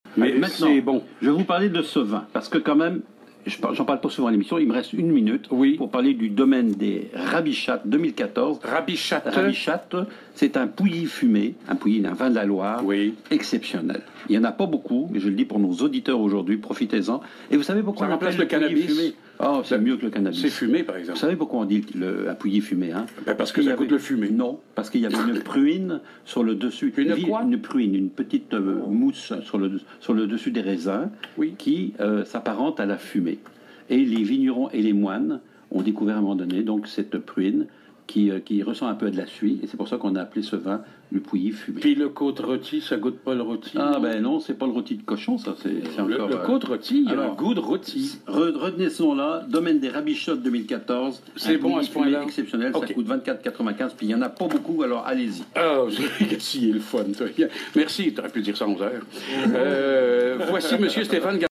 Émission du 24 octobre 2015 sur les ondes de Joël Le Bigot.